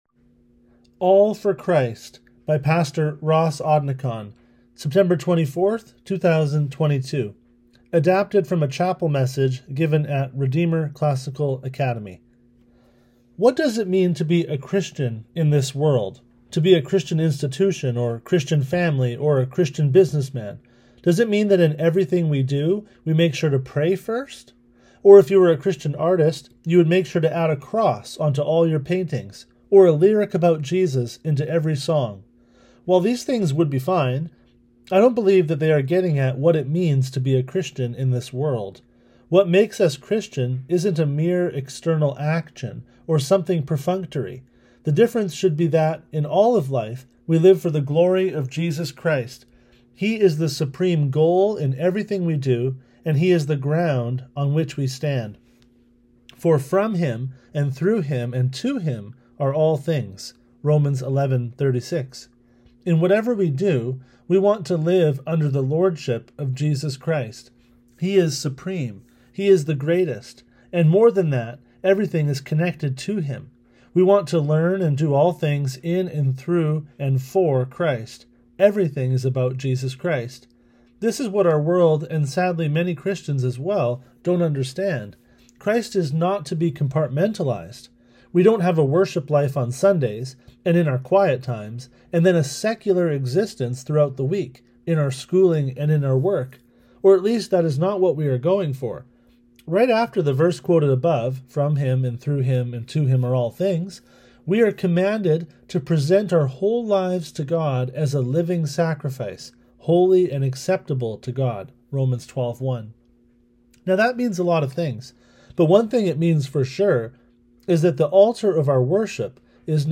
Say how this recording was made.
[Adapted from a chapel message given at Redeemer Classical Academy]